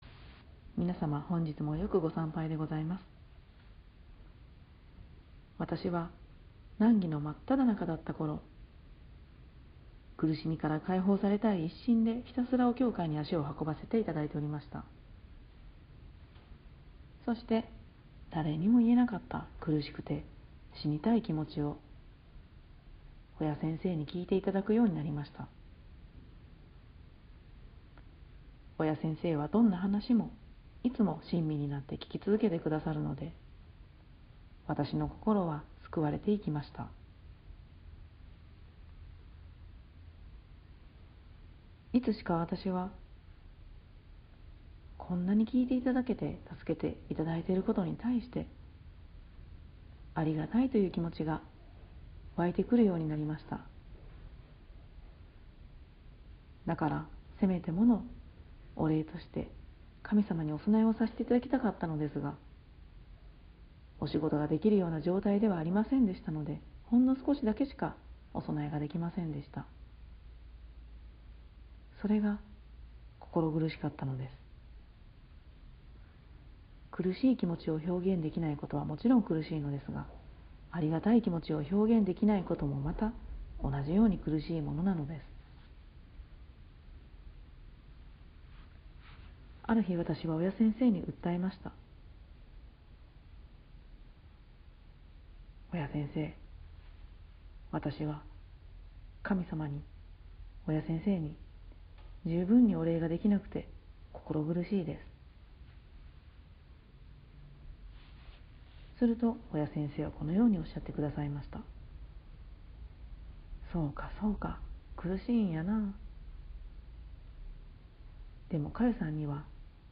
【教話】 第七集「苦しみの根を断ち切る」(MP3)
金光教平戸教会 御祈念後 教話